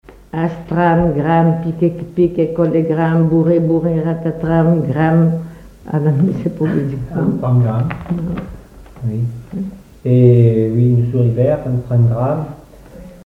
enfantine : comptine
collecte en Vendée
Pièce musicale inédite